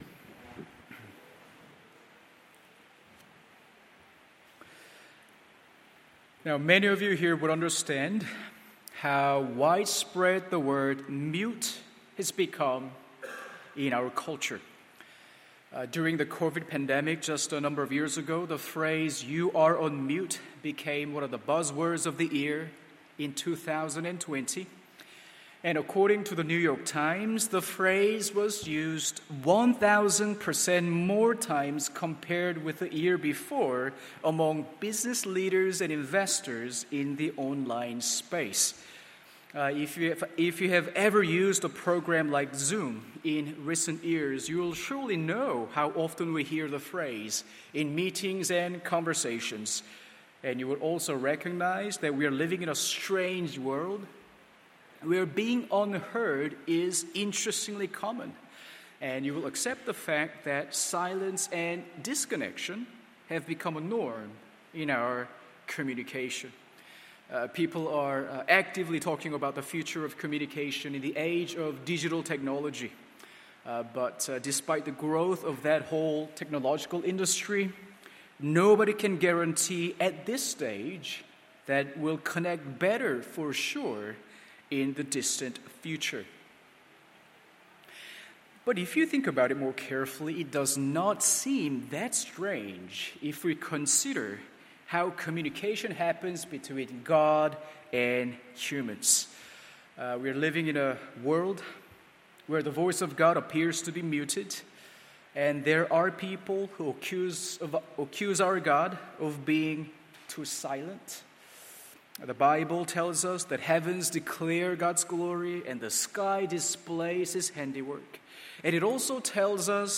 MORNING SERVICE Psalm 19…